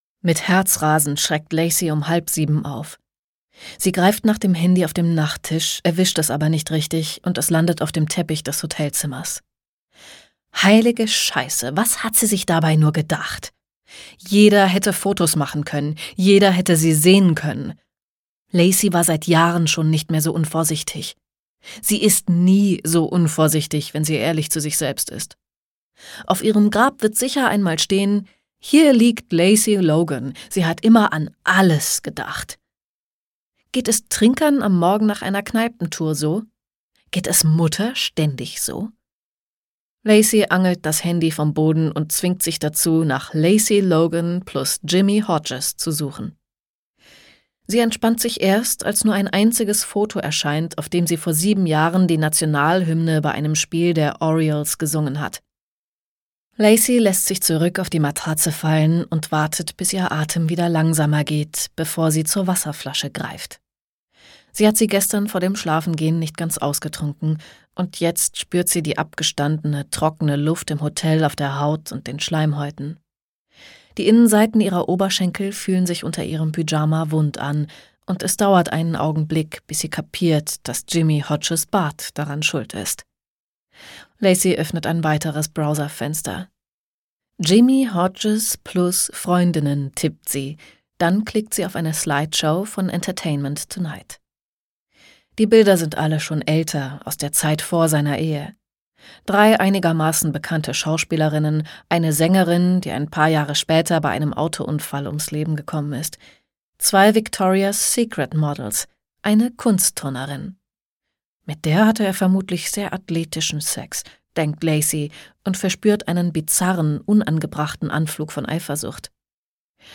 A League for Love - Katie Cotugno | argon hörbuch
Gekürzt Autorisierte, d.h. von Autor:innen und / oder Verlagen freigegebene, bearbeitete Fassung.